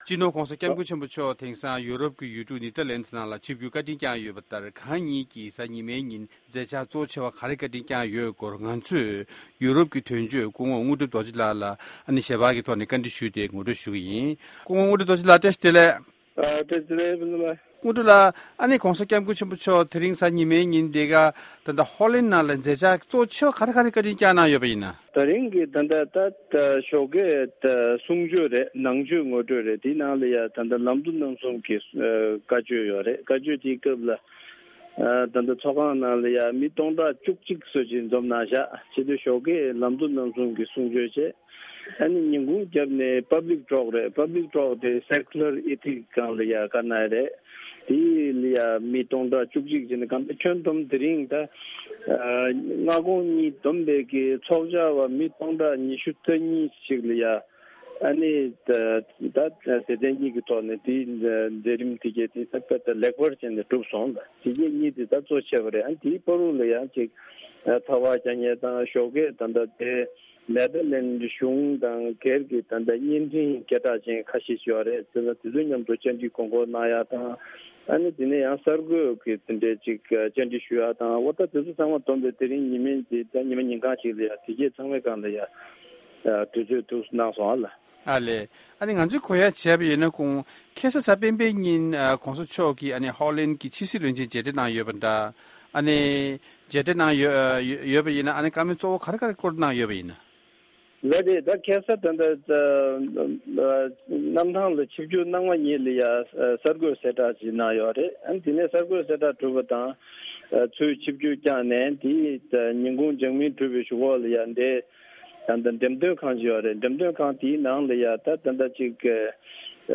༧གོང་ས་མཆོག་གིས་ལམ་གཙོ་རྣམ་གསུམ་དང་མང་ཚོགས་ལ་བཟང་སྤྱོད་ཀྱི་སྐོར་བཀའ་སློབ།